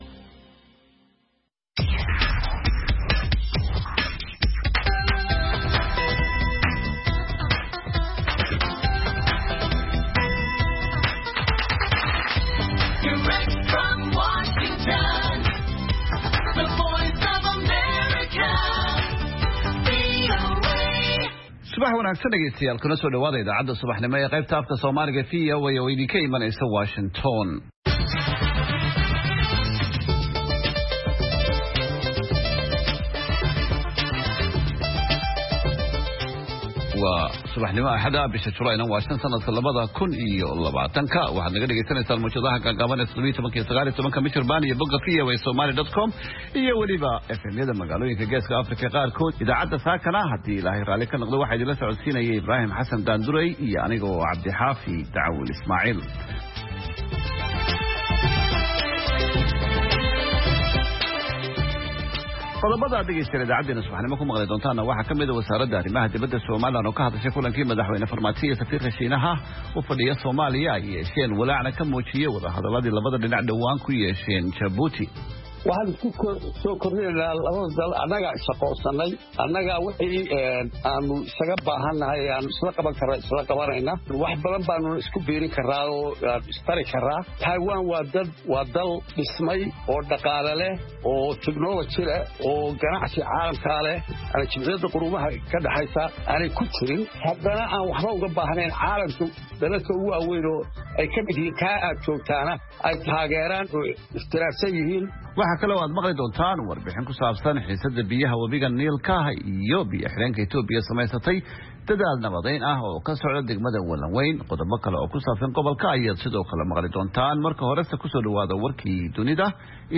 Idaacadda Subaxnimo ee Saaka iyo Caalamka waxaad ku maqashaa wararkii habeenimadii xalay ka dhacay Soomaaliya iyo waliba caalamka, barnaamijyo, wareysi xiiso leh, ciyaaraha, dhanbaallada dhagaystayaasha iyo waliba wargeysyada caalamku waxay saaka ku waabariisteen.